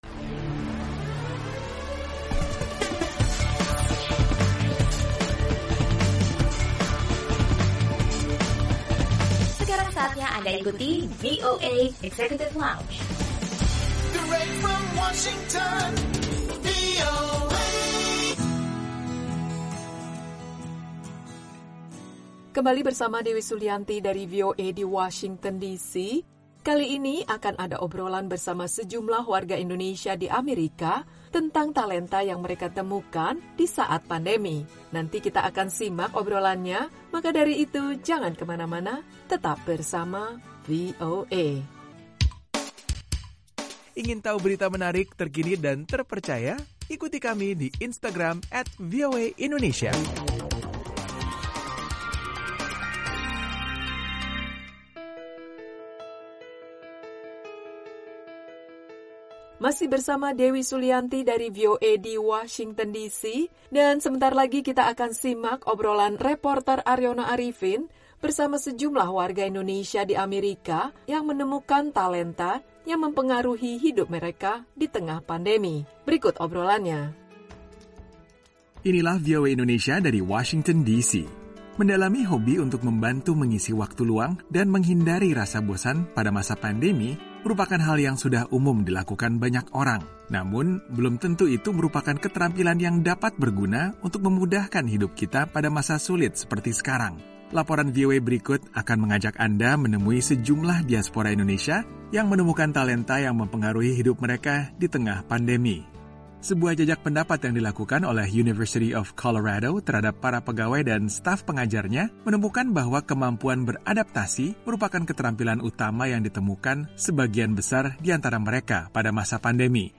Obrolan bersama sejumlah warga Indonesia di Amerika tentang talenta yang mereka temukan di saat pandemi.